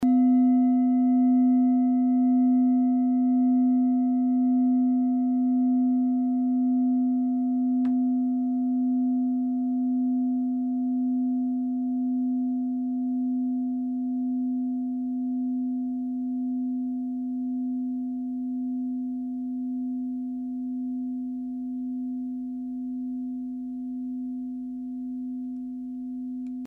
Klangschale TIBET Nr.2
Sie ist neu und ist gezielt nach altem 7-Metalle-Rezept in Handarbeit gezogen und gehämmert worden..
Die Frequenz des Sonnentons liegt bei 126,2 Hz und dessen tieferen und höheren Oktaven. In unserer Tonleiter ist das nahe beim "H".
klangschale-tibet-2.mp3